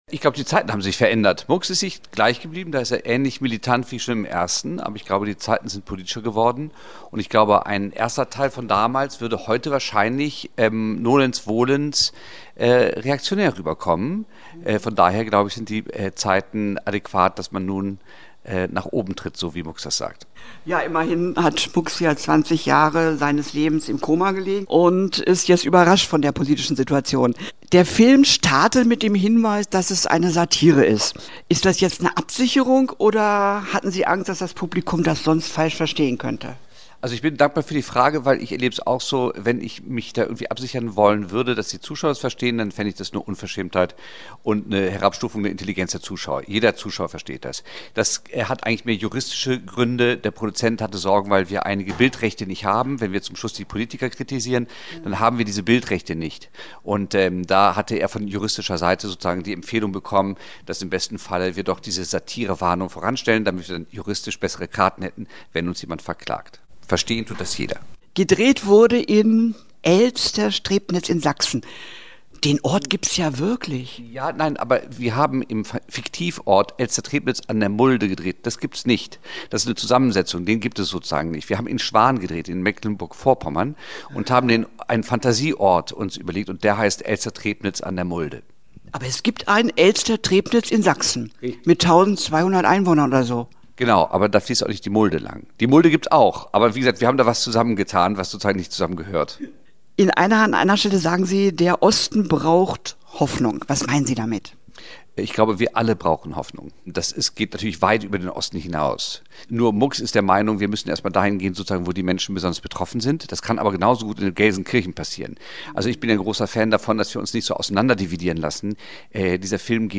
Interview-Mux-hoch-X-Stahlberg.mp3